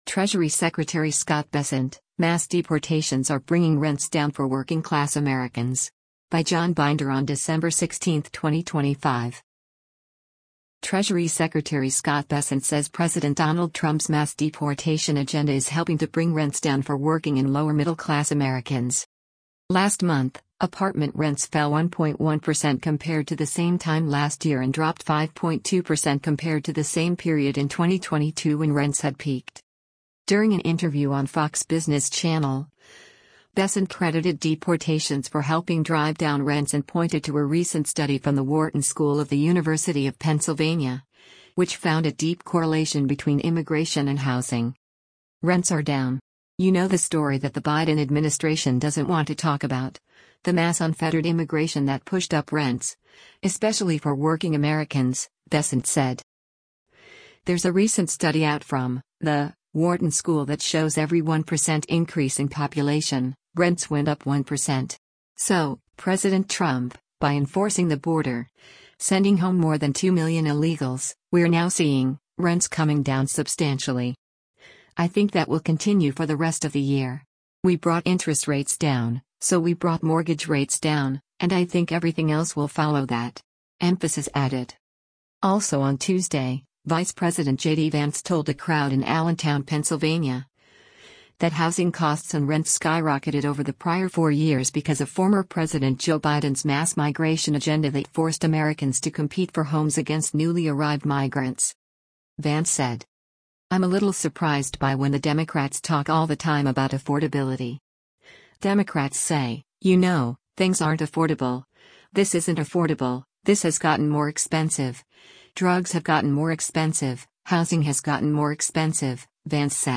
During an interview on Fox Business Channel, Bessent credited deportations for helping drive down rents and pointed to a recent study from the Wharton School of the University of Pennsylvania, which found a deep correlation between immigration and housing.